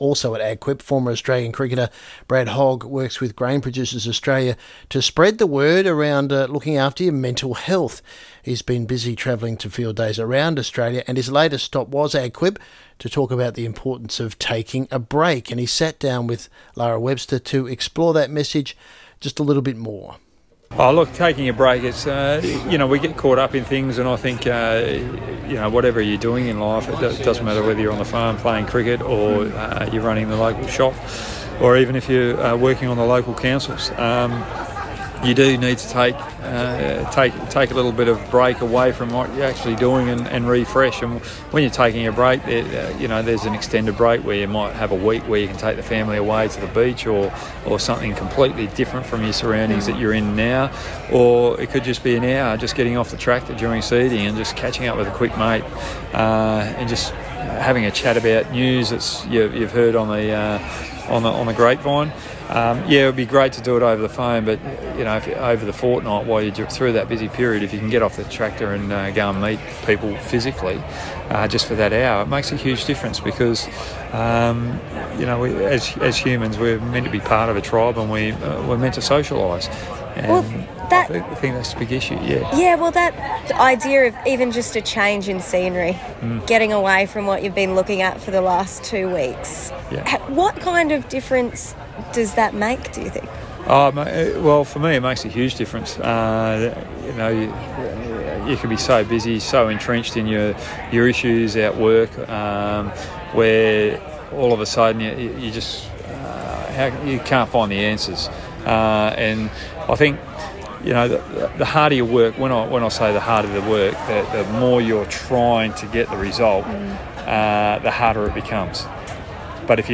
ABC NSW Country Hour | Brad Hogg Interview at AgQuip